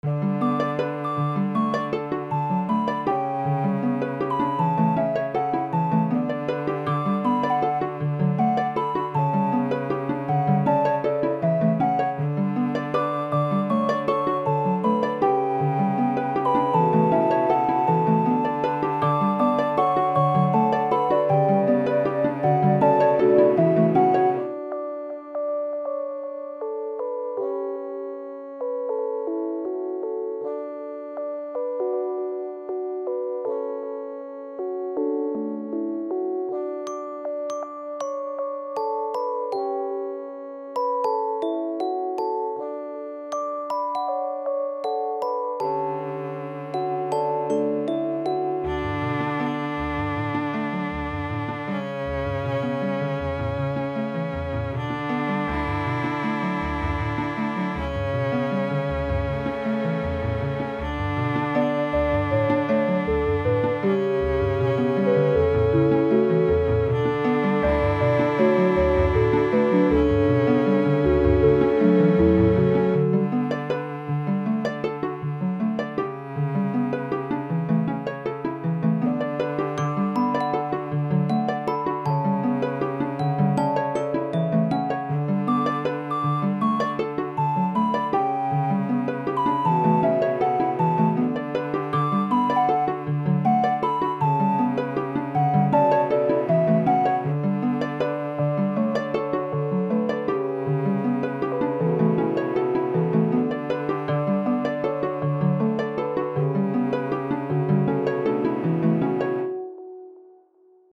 Music / Game Music
violin gamemusic